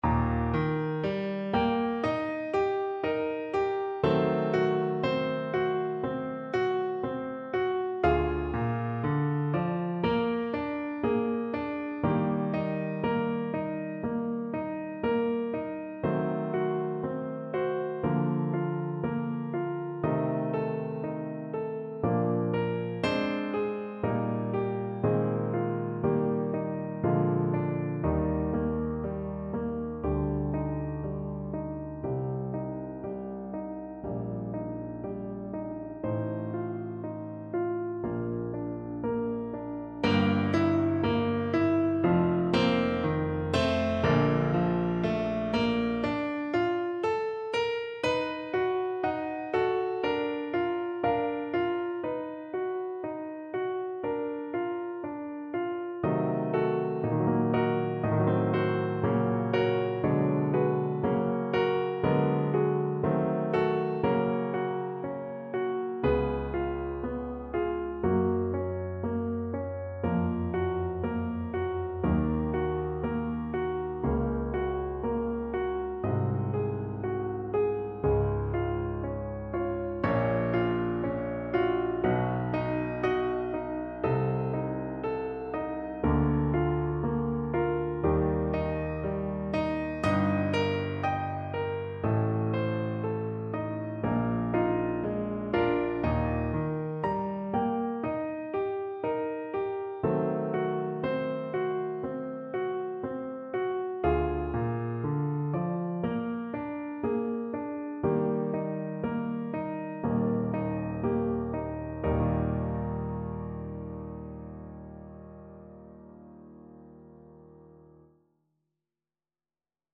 Piano version
No parts available for this pieces as it is for solo piano.
4/4 (View more 4/4 Music)
Ziemlich langsam
Piano  (View more Intermediate Piano Music)
Classical (View more Classical Piano Music)